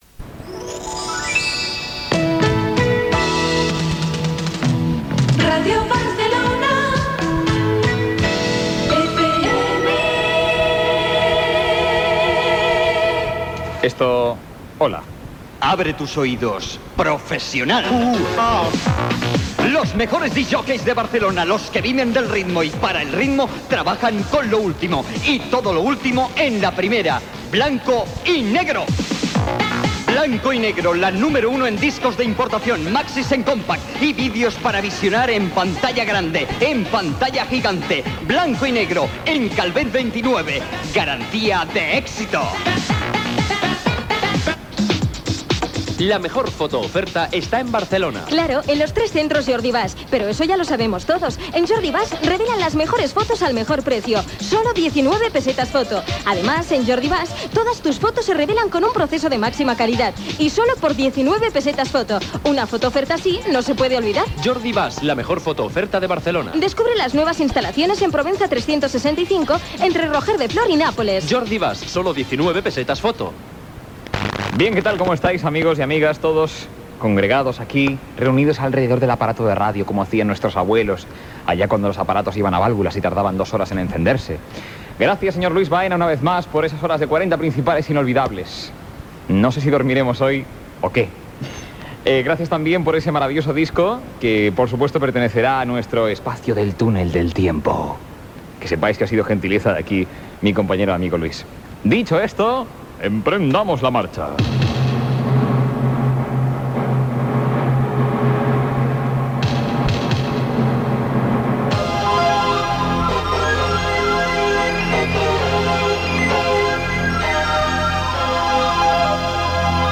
Indicatiu de l'emissora, publicitat, agraïments, un dia plujós i indicatiu del presentador
Musical